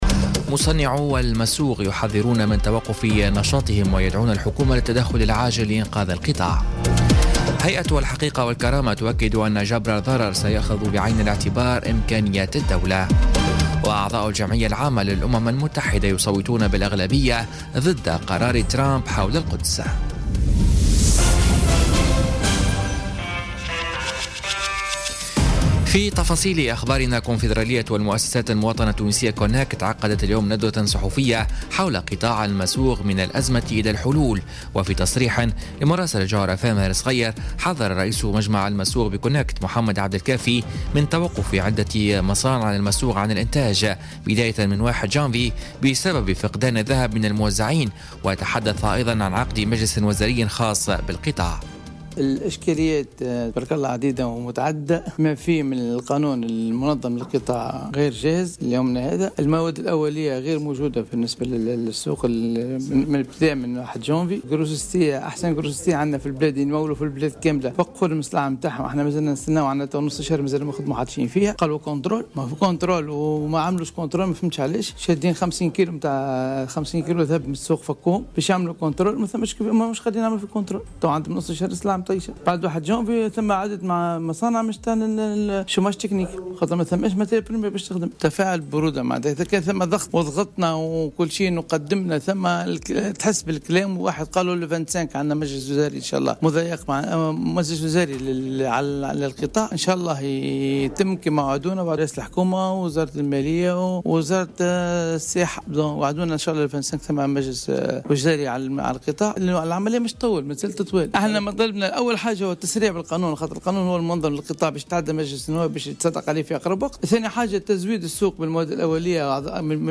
نشرة أخبار السابعة مساءً ليوم الخميس 21 ديسمبر 2017